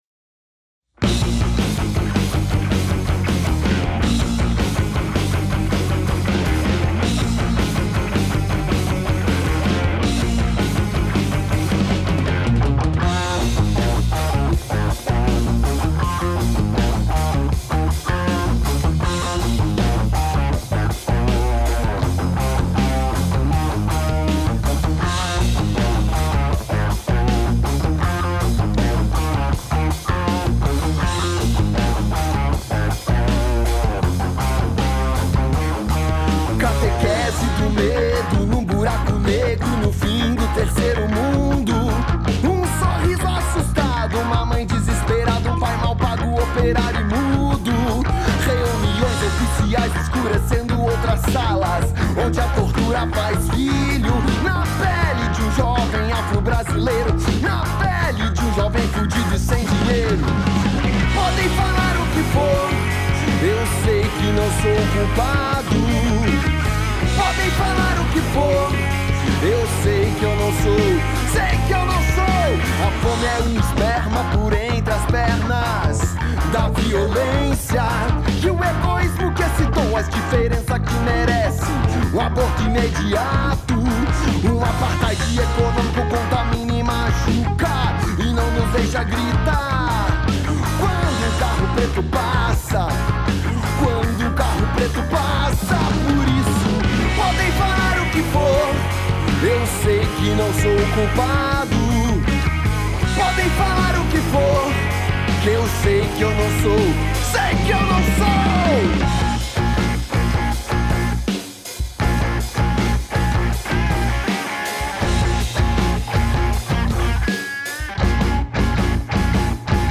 Baixo
Bateria
Guitarra e voz